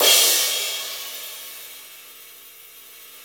CYM XRIDE 2D.wav